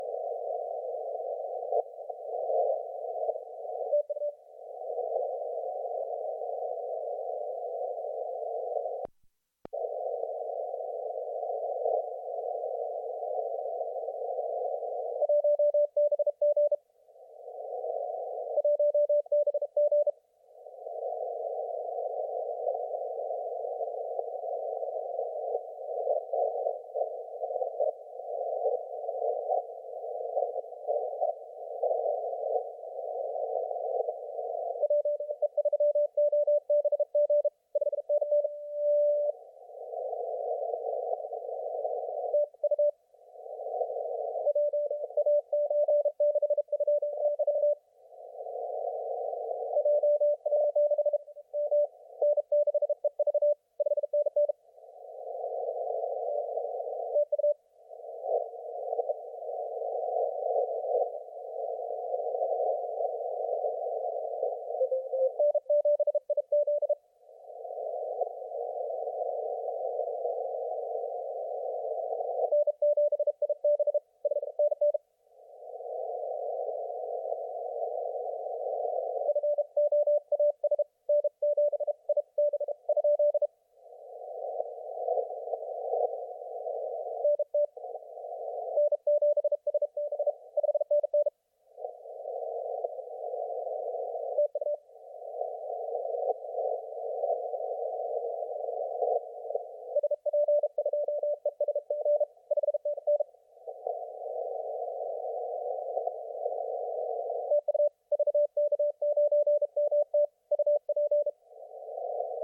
VK9MT 20 CW